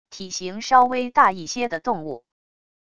体型稍微大一些的动物wav音频